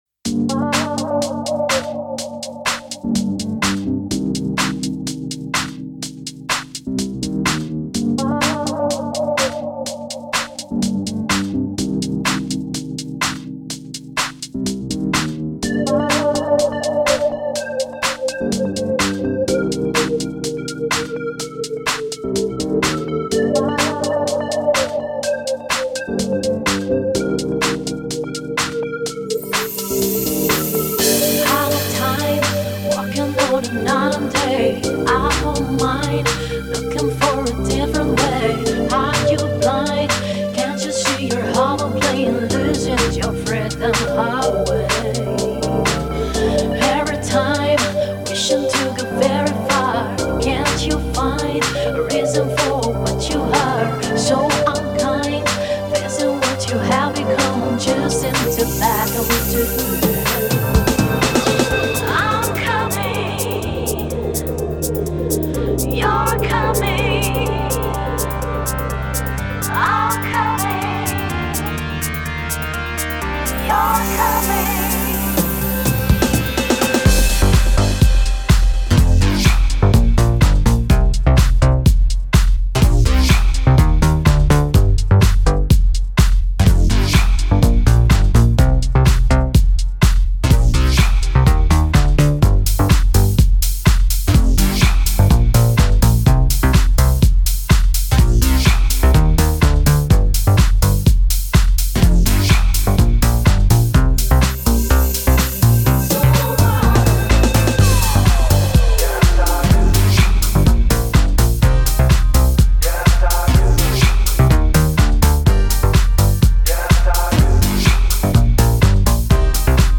Mixed for broadcast